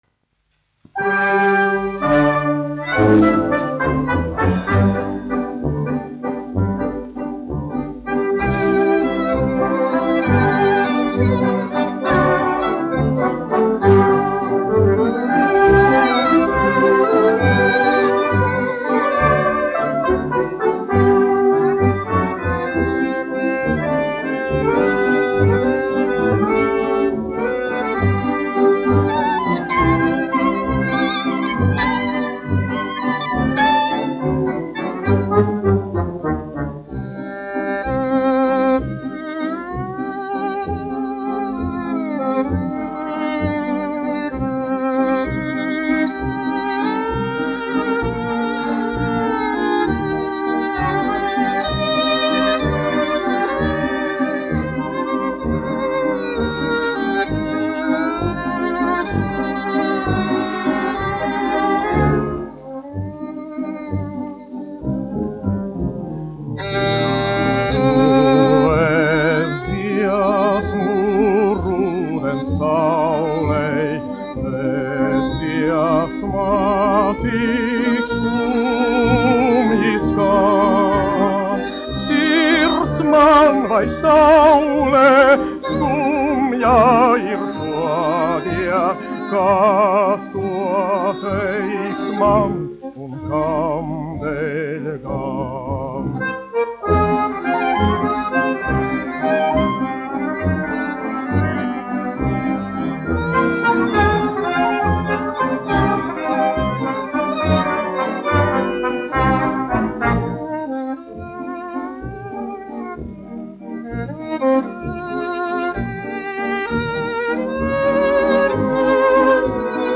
1 skpl. : analogs, 78 apgr/min, mono ; 25 cm
Valši
Latvijas vēsturiskie šellaka skaņuplašu ieraksti (Kolekcija)